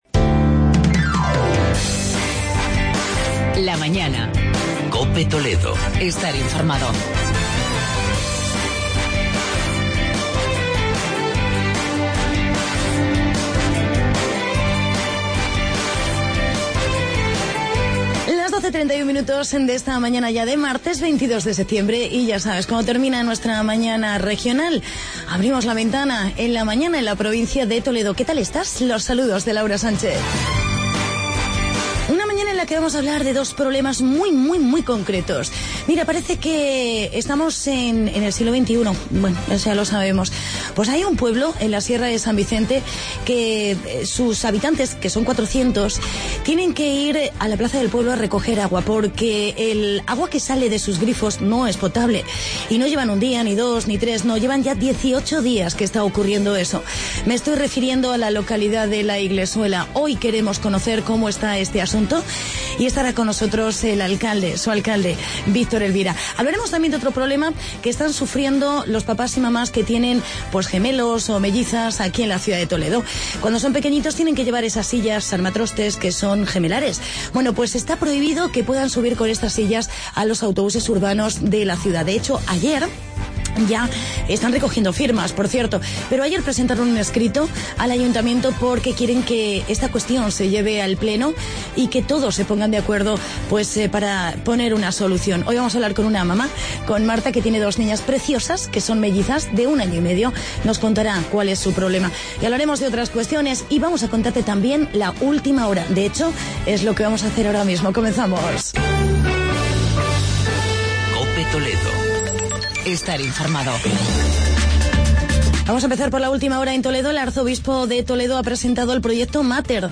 Entrevista con el alcalde de la Iglesuela, Victor Elvira